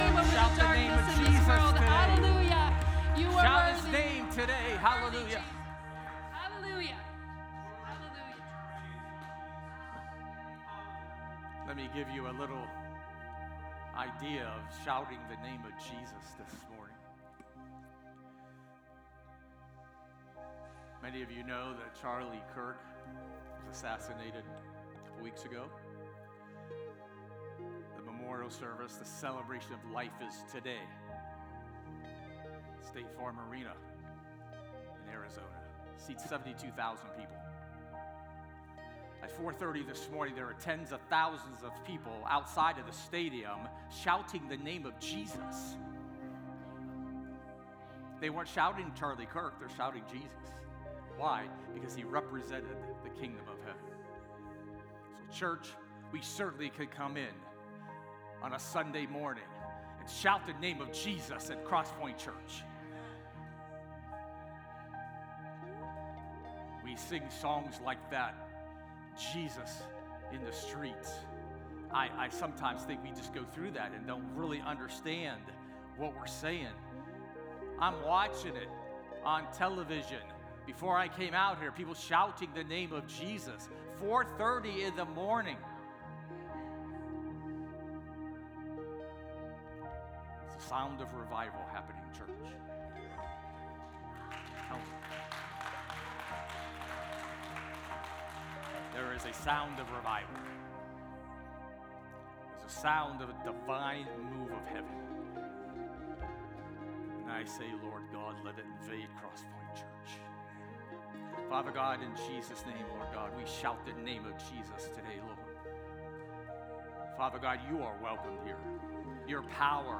Media — Cross Pointe Church